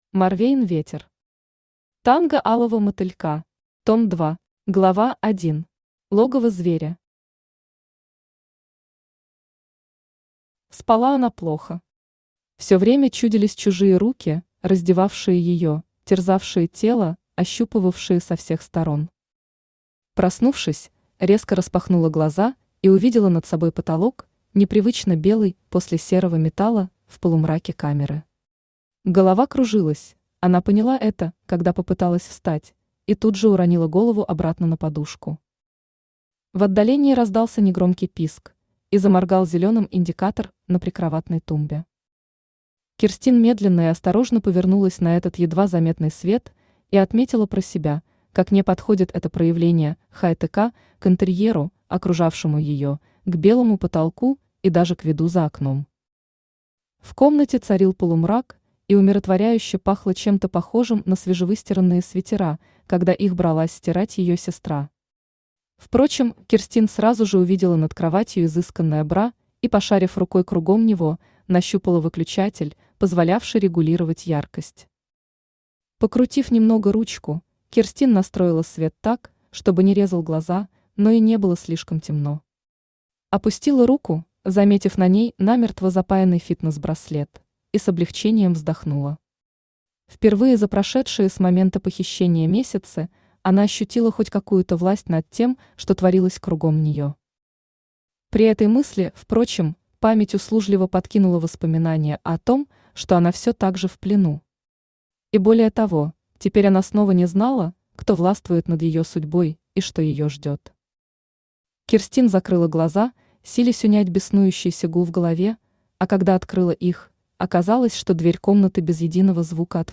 Аудиокнига Танго алого мотылька. Том 2 | Библиотека аудиокниг
Том 2 Автор Морвейн Ветер Читает аудиокнигу Авточтец ЛитРес.